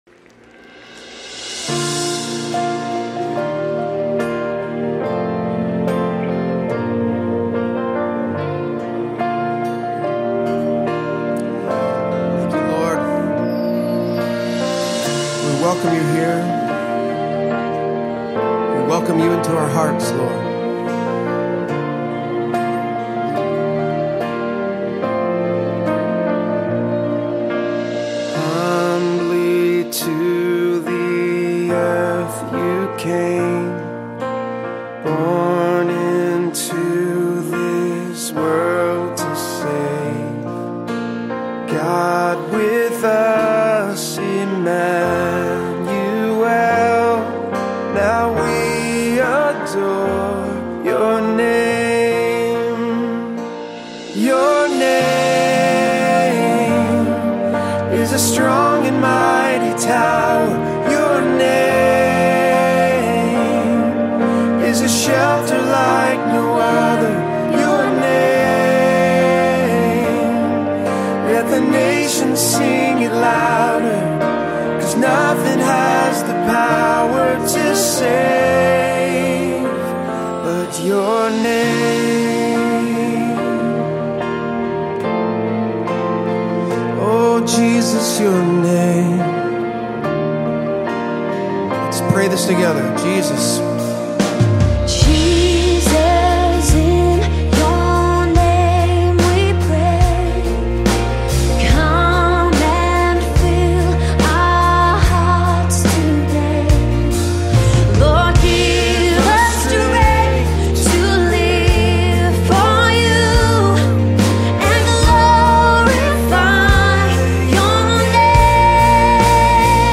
Worship Songs